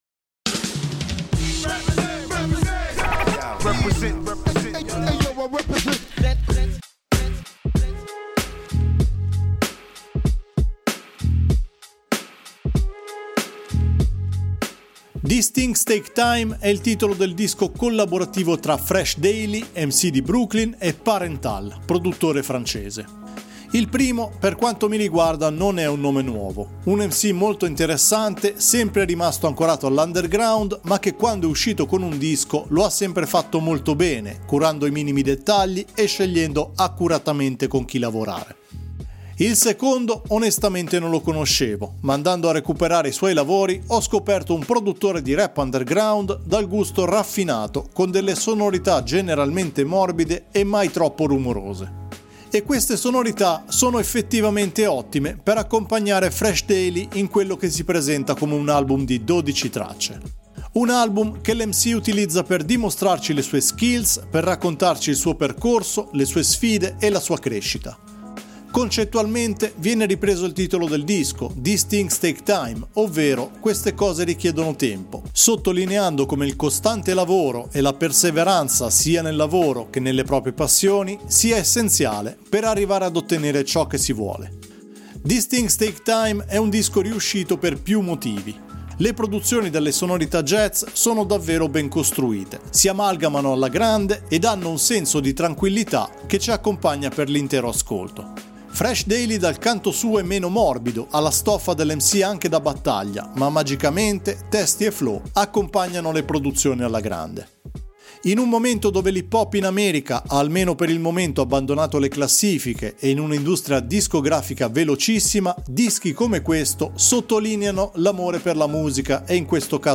dalle sonorità morbide e rilassate